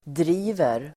Uttal: [dr'i:ver]